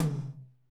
Index of /90_sSampleCDs/Northstar - Drumscapes Roland/DRM_R&B Groove/TOM_R&B Toms x
TOM R B H0CL.wav